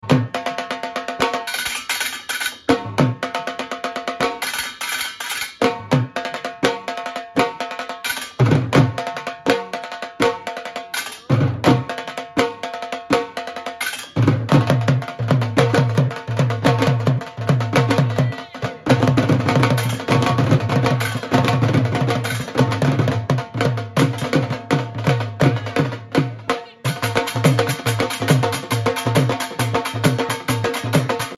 Dhol beat